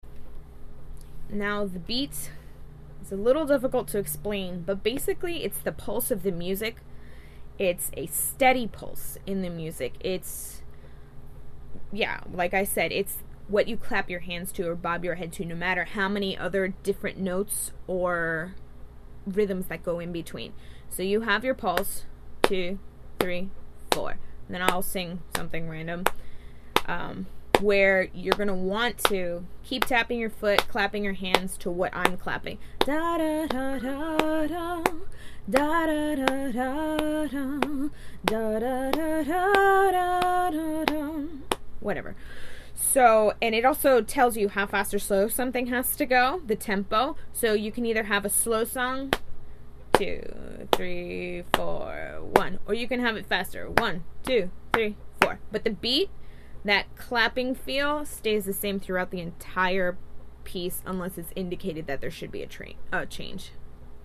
The Beat  – The regular pulse of the music. It’s what you clap your hands to or bob your head to or tap your toes to. It establishes tempo, how fast or slow something is. It gives the song or piece its “heartbeat”.
mekdost-the-beat.mp3